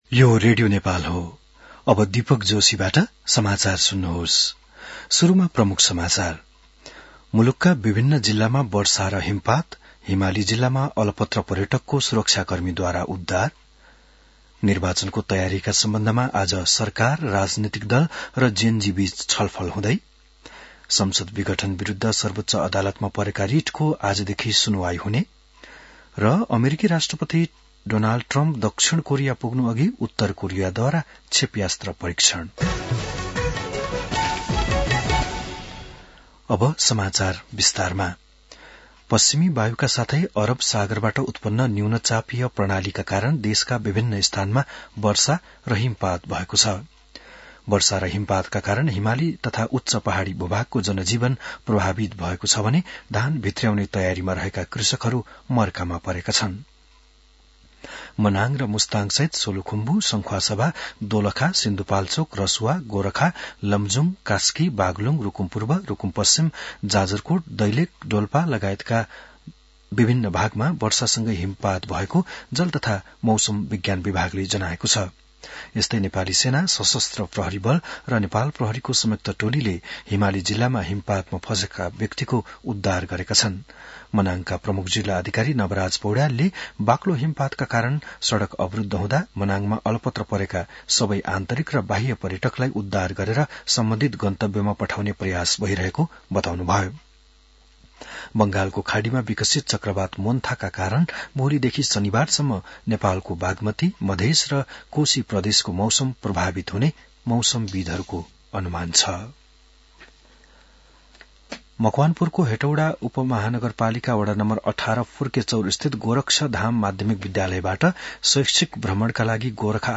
बिहान ९ बजेको नेपाली समाचार : १२ कार्तिक , २०८२